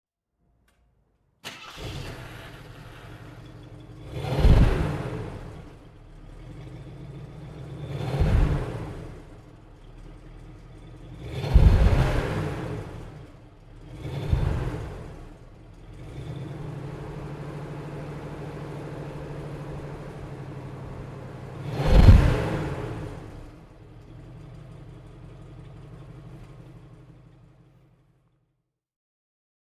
AMC Gremlin X (1976) - Starten und Leerlauf
AMC_Gremlin_1976.mp3